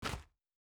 Land Step Gravel B.wav